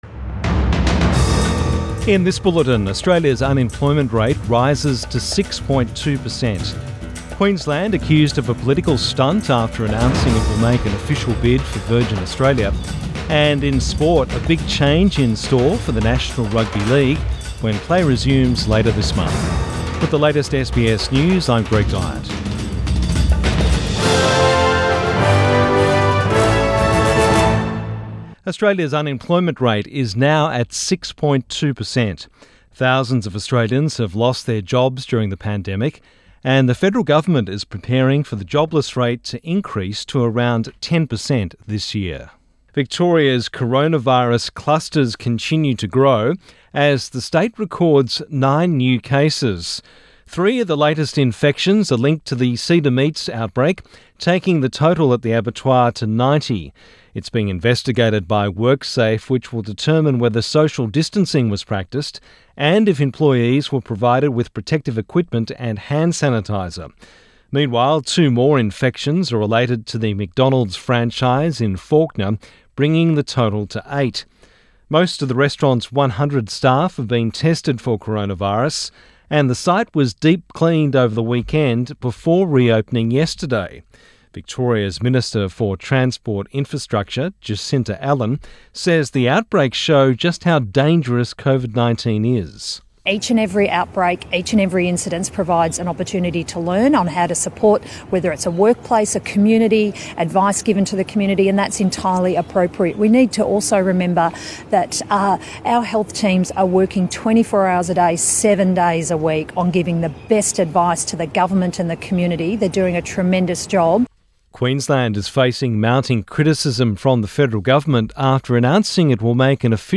Midday bulletin 14 May 2020